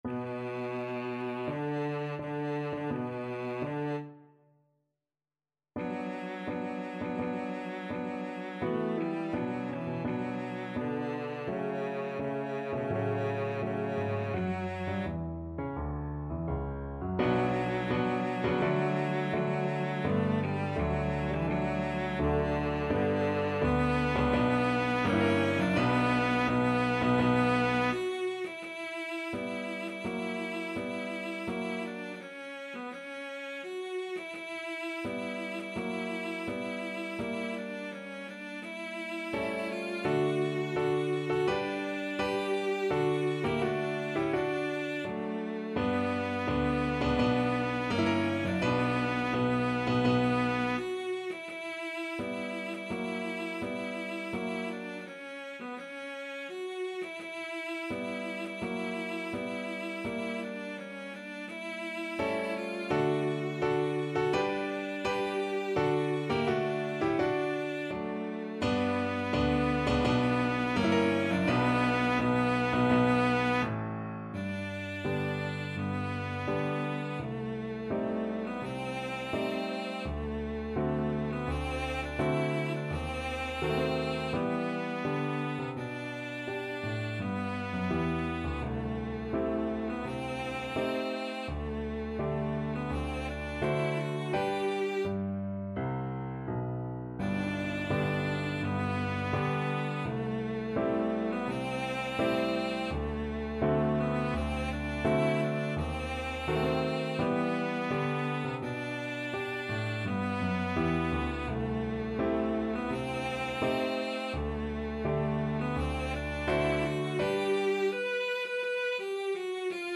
Cello version
4/4 (View more 4/4 Music)
Tempo di Marcia =84
Classical (View more Classical Cello Music)